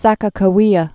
(săkə-kə-wēə), Lake